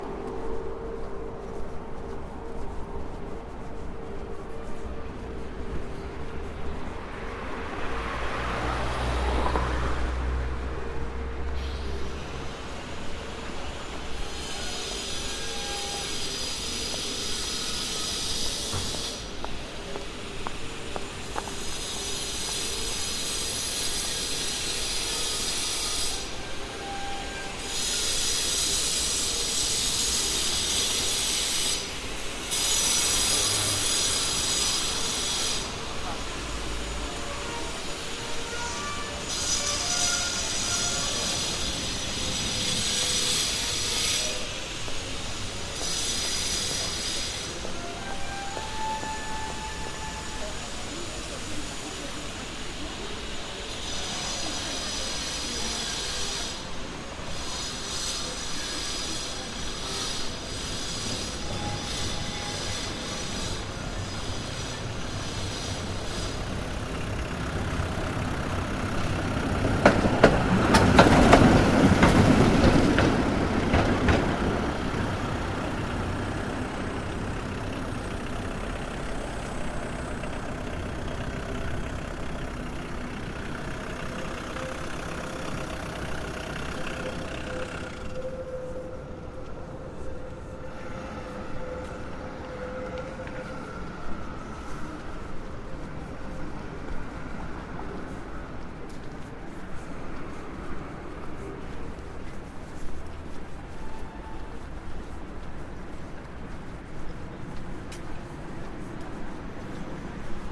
Здесь вы услышите гул мостовых, крики торговцев, звон трамваев и другие характерные шумы ушедших эпох.
Шум советского индустриального города